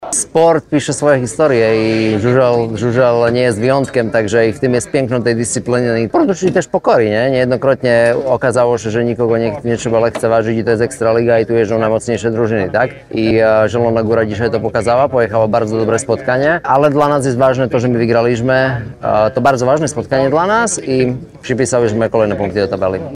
– Mówił po meczu Martin Vaculik